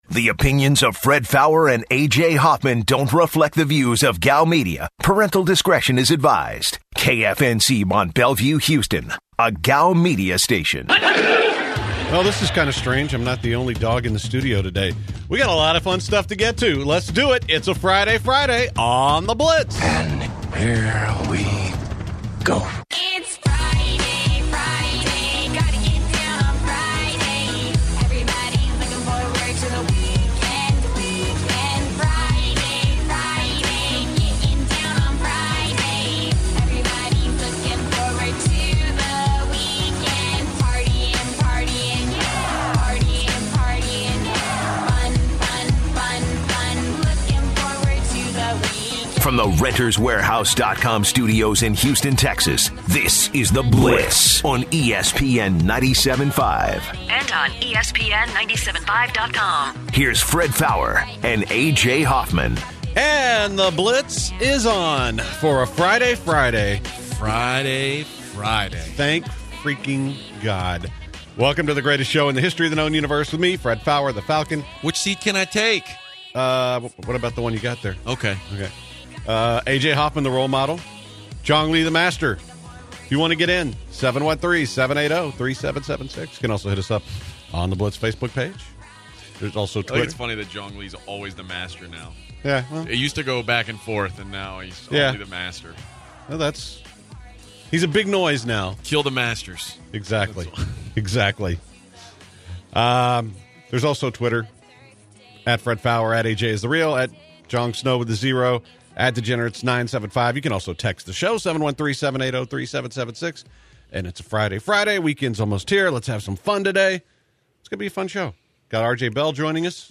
After that, the Blitz talks some Astros, including who they should target and who they’ve been rumored to be targeting. Finally, a caller chimes in and proposes an interesting LeBron trade that could be made with the Rockets.